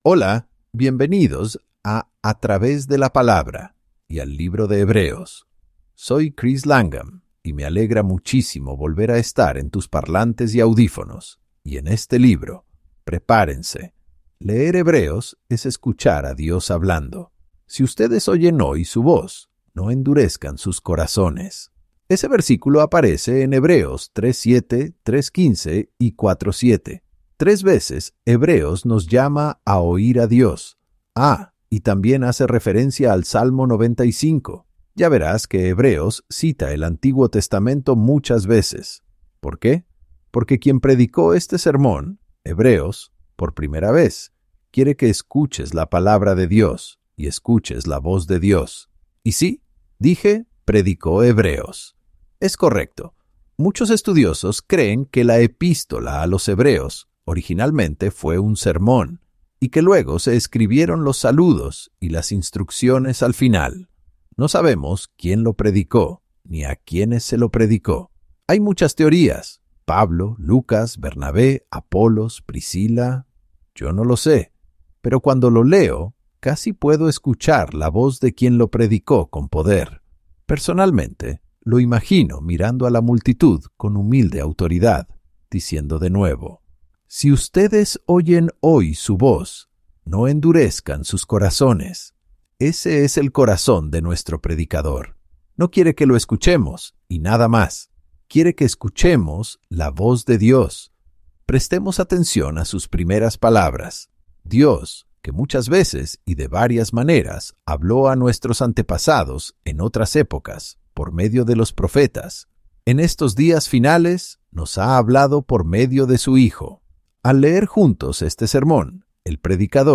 Divulgación Legal Para el beneficio de nuestros oyentes, TTW Bible Audio Guides (Guías de audio de “A Través de la Palabra”) utiliza tecnologías de IA para recrear las voces de los maestros en diferentes idiomas, con el apoyo de la supervisión humana y la garantía de calidad.